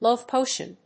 アクセントlóve pòtion